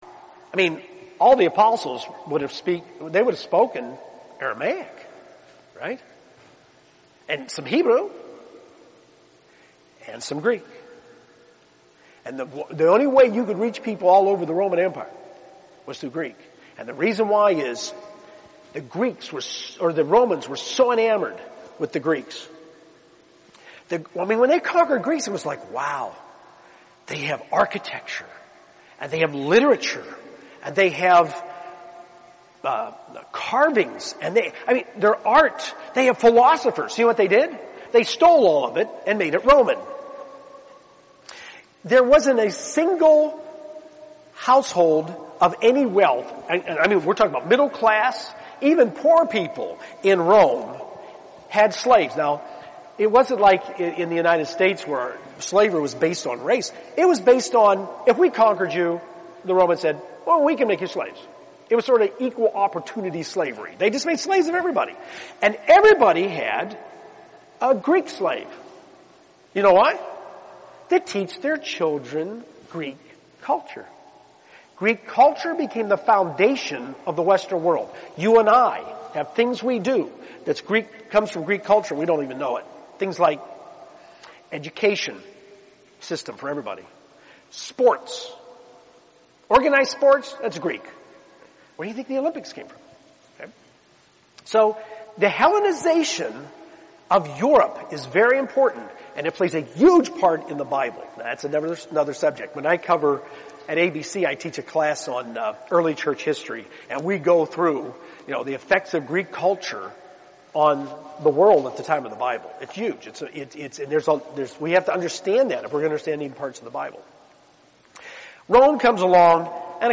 This sermon was given at the Galveston, Texas 2018 Feast site.